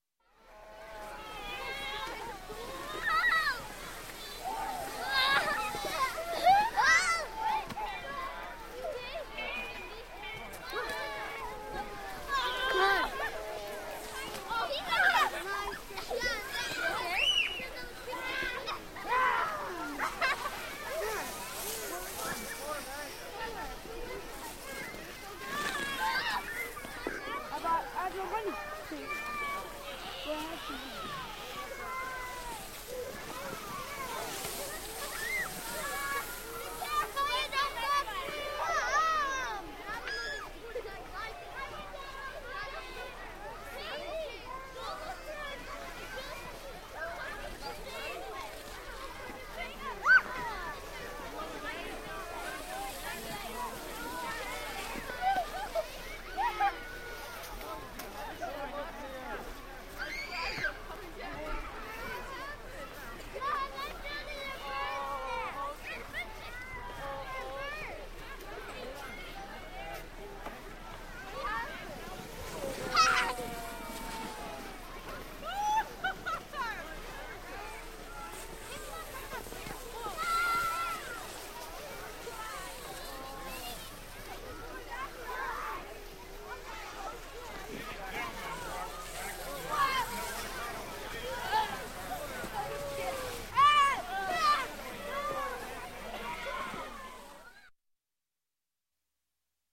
На этой странице собраны звуки, характерные для детского лагеря: смех ребят, шум игр на свежем воздухе, вечерние посиделки с гитарой и другие атмосферные моменты.
Звуки радостного плескания в реке